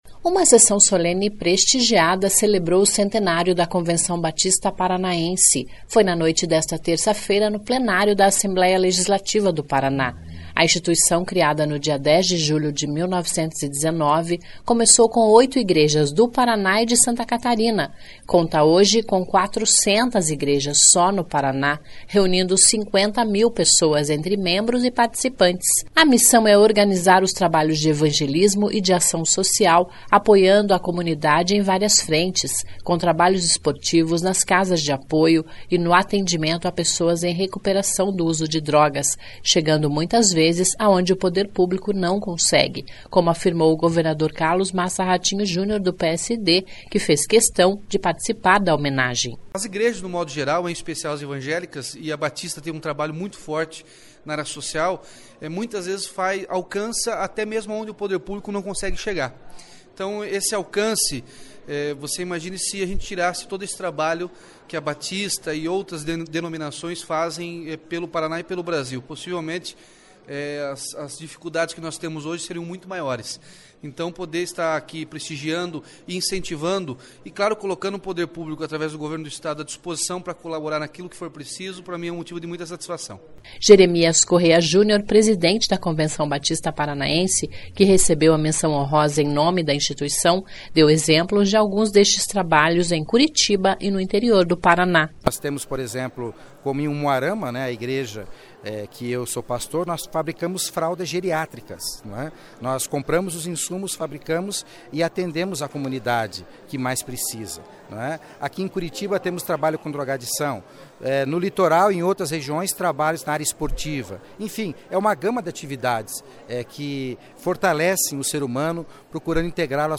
Uma sessão solene prestigiada celebrou o centenário da Convenção Batista Paranaense. Foi na noite desta terça-feira (18) no Plenário da Assembleia Legislativa do Paraná (Alep).